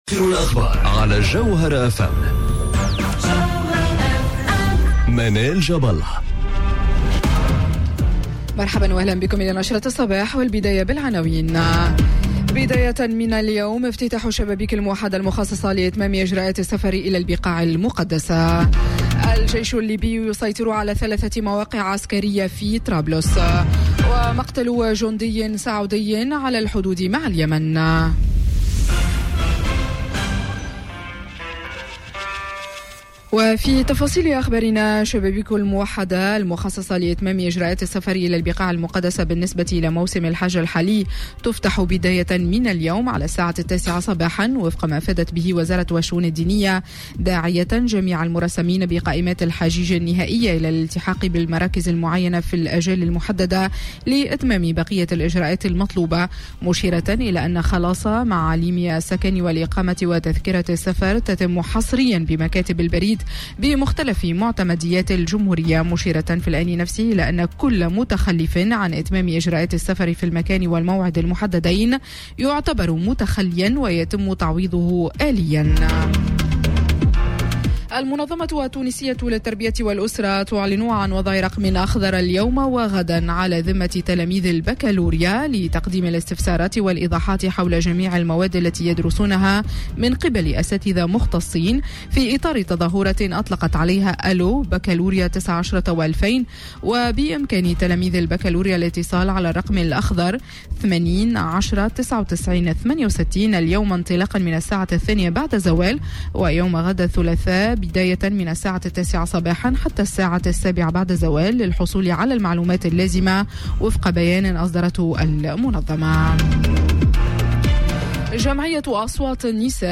نشرة أخبار السابعة صباحا ليوم الإثنين 10 جوان 2019